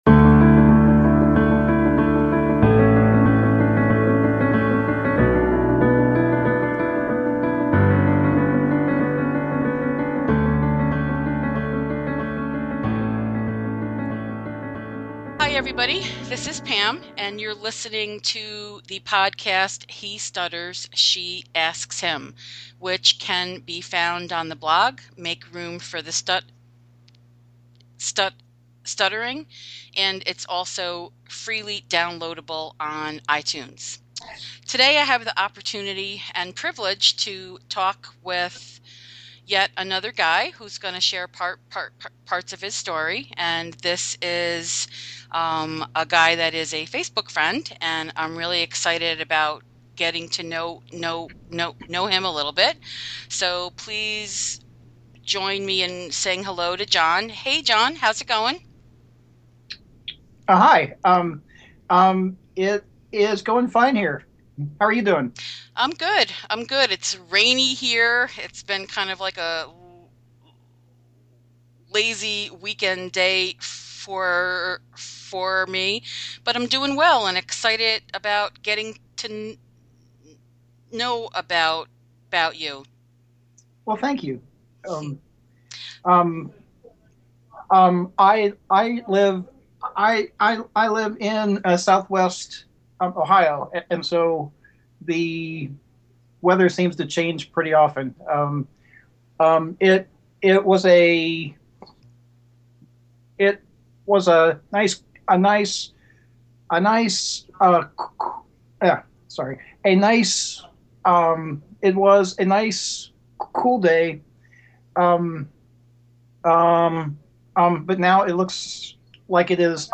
We have a great conversation, with lots of laughs.